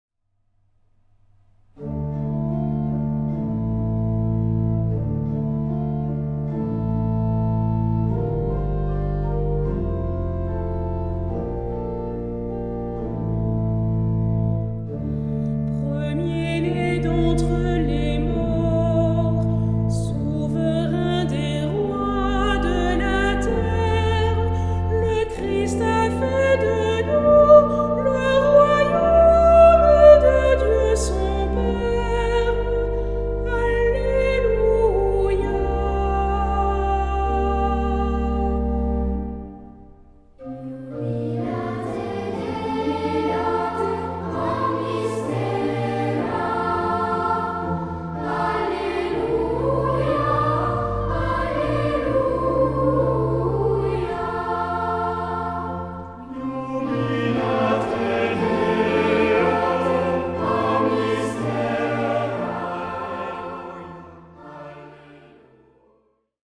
Genre-Stil-Form: Tropar ; Psalmodie
Charakter des Stückes: andächtig
Instrumente: Orgel (1) ; Melodieinstrument (ad lib)
Tonart(en): d-moll